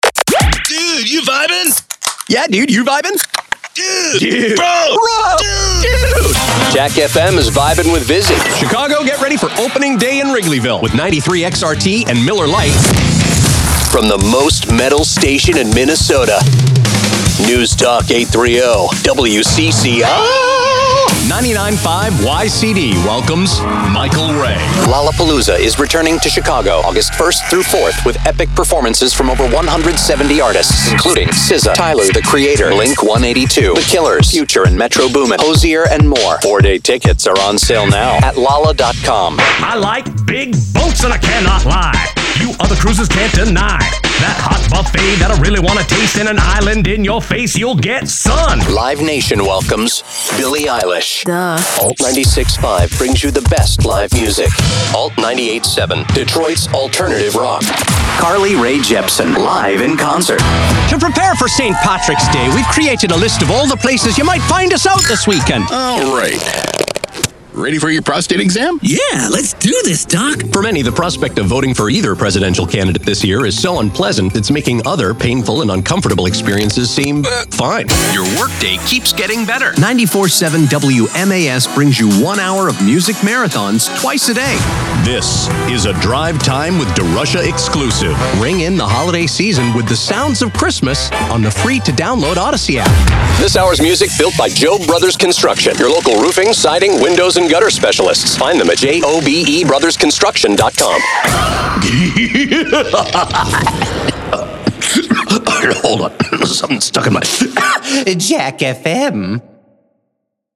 Male
Adult (30-50), Older Sound (50+)
For commercial work, everything from friendly, natural, and conversational, to deep, gravelly, and authoritative.
Radio / TV Imaging
Words that describe my voice are conversational, deep, gravelly.